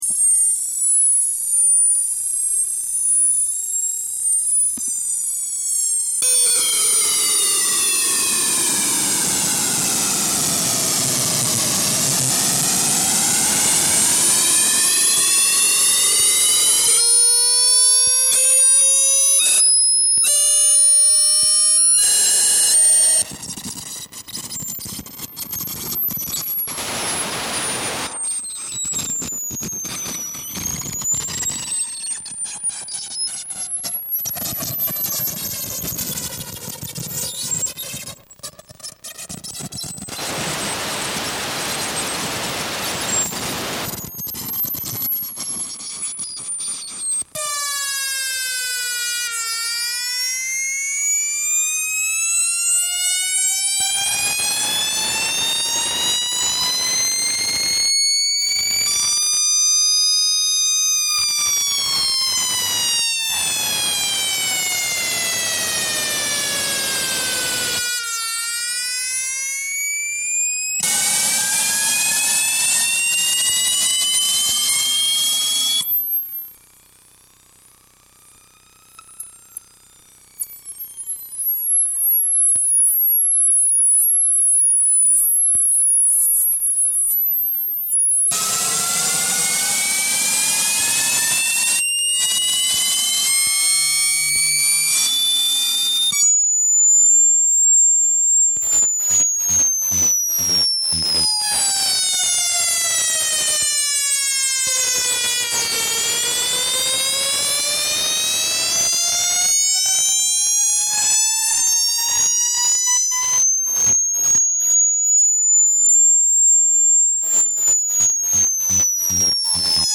The picture shown is pre-re-housing.